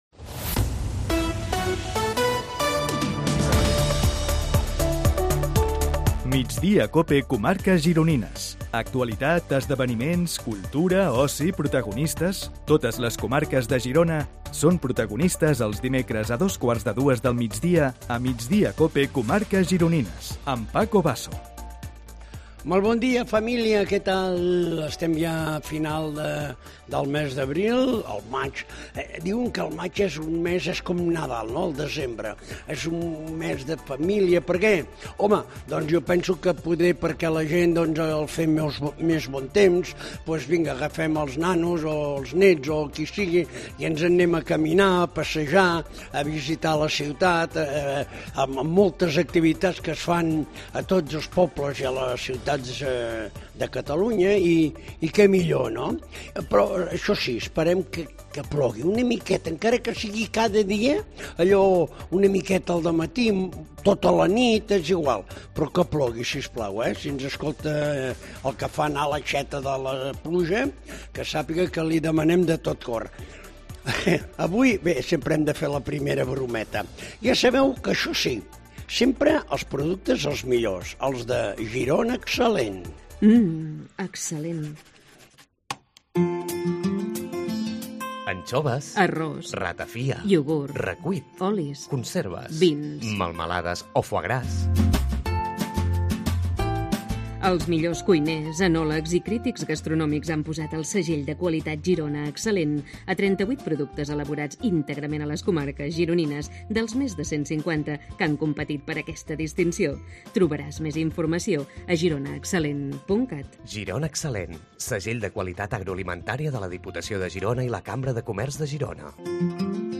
AUDIO: Entrevistem els protagonistes de l'actualitat a les comarques gironines